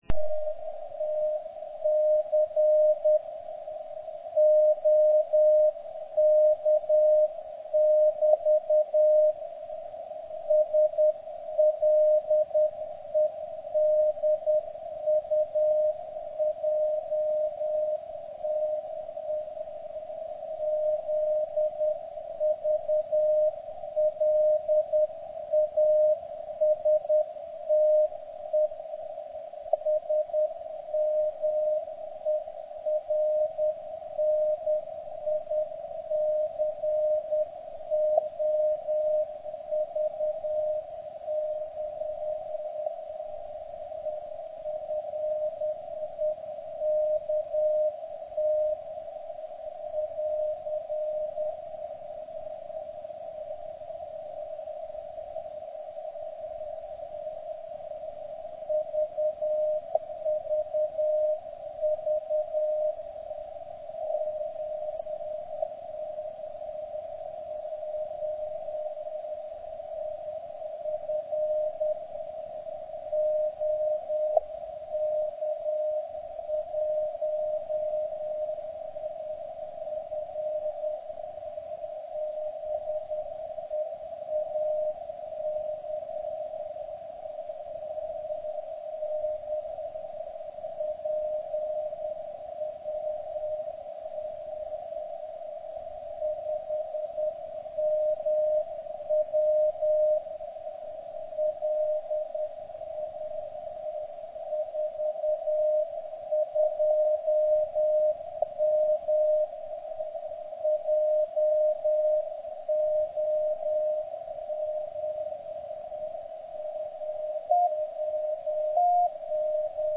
11.00 SEC (*.mp3 300KB)  Asi nejsilnější signál. Opět kombinace Es a směrnicového efektu